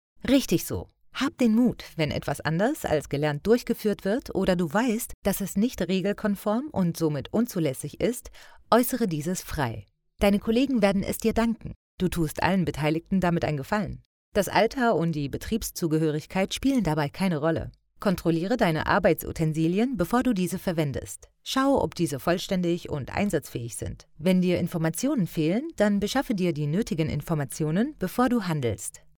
Meine Stimme ist warm, klar und vielseitig einsetzbar – ob für Werbung, Imagefilme, E‑Learnings, Audioguides oder Dokumentationen.
Kund:innen schätzen meinen natürlichen, empathischen Ton, die präzise Aussprache und eine zuverlässige, unkomplizierte Zusammenarbeit.
Broadcast‑ready Aufnahmen entstehen in meinem eigenen Studio in Berlin.
Trilinguale Sprecherin (Deutsch, Englisch & Niederländisch) für Werbung, Imagefilme & Erklärvideos – authentisch & professionell.
Sprechprobe: eLearning (Muttersprache):